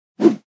whoosh_out.wav